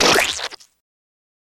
Big Mud Splat On Face Gross